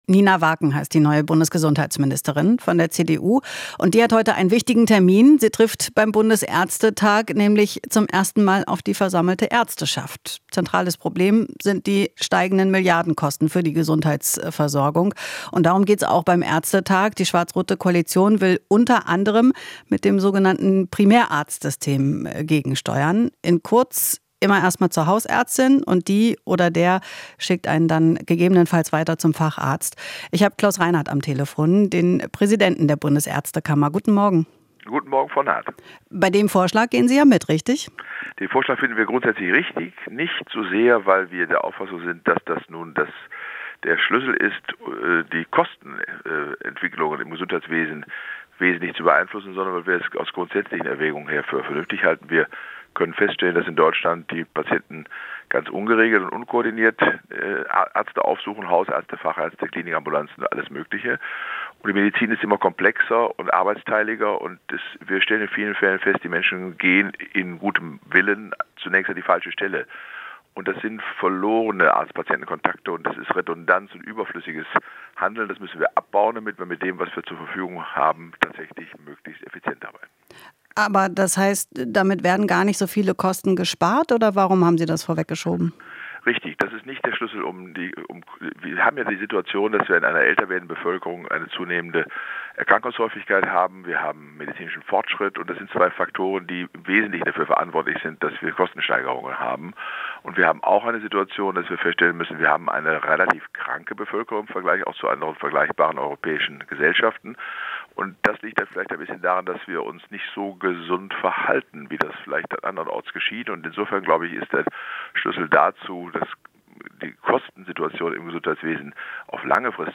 Interview - Ärztekammer: Primärarztsystem kein Schlüssel, um Kosten zu sparen